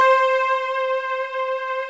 CRYSTAL C4.wav